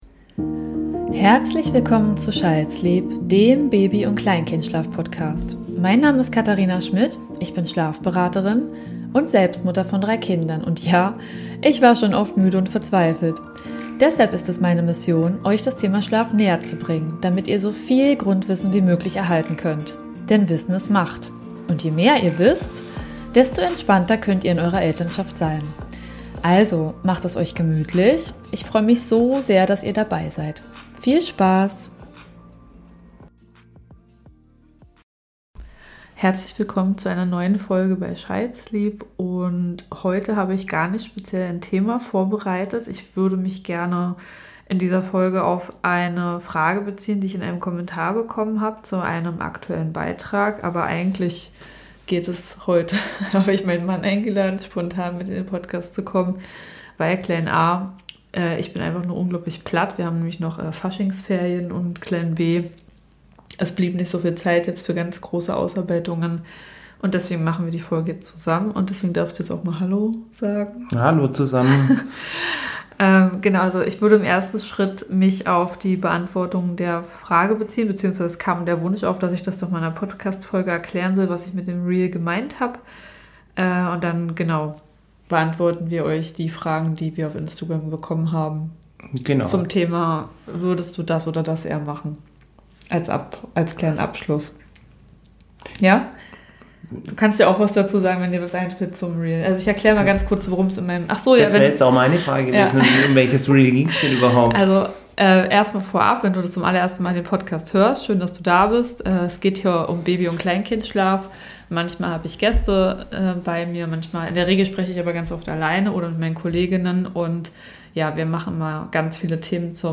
Wie oft braucht dein Baby Hilfe, um Schlafzyklen zu verbinden? In dieser Folge sprechen mein Mann und ich darüber, wann und wie du unterstützen kannst und spielen eine Runde „Wer würde eher...?“ im Q&A.  Ganz viel Spaß mit dieser Folge!